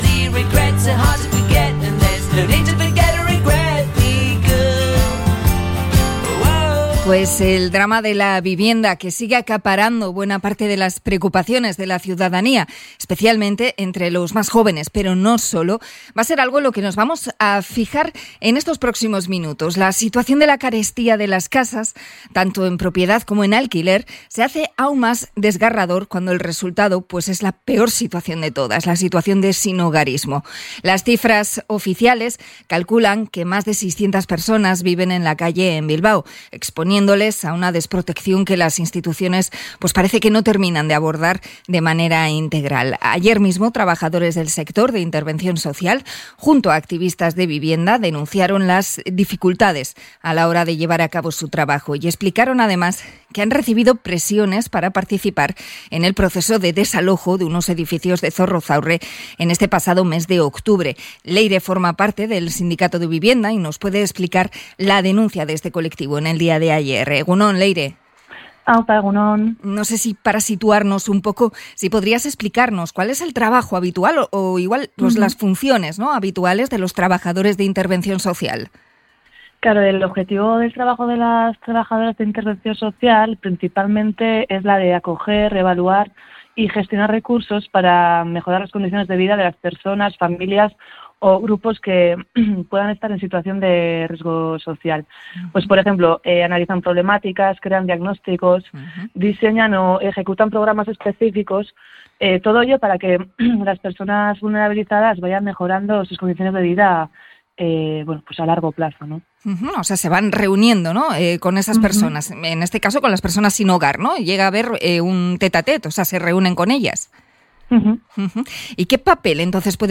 Entrevista al sindicato de la vivienda por recibir presiones en su trabajo